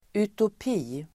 Ladda ner uttalet
Uttal: [utop'i:]